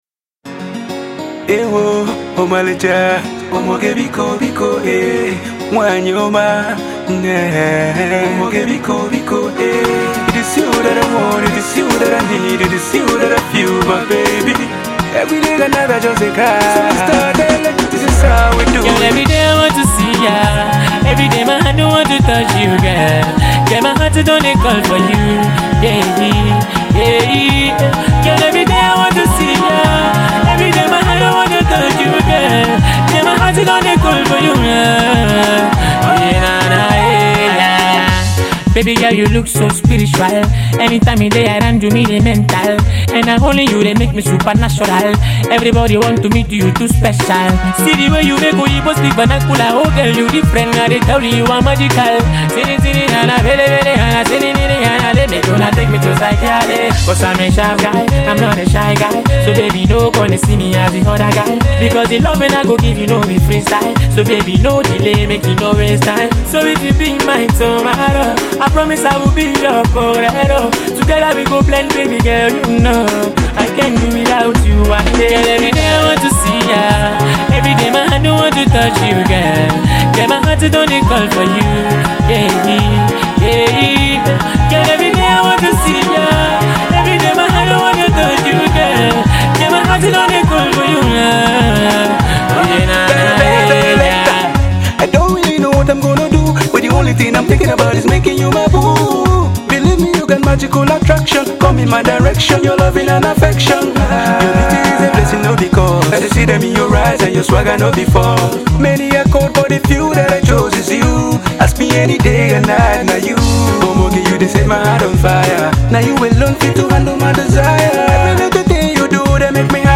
Africana-meets-Pop single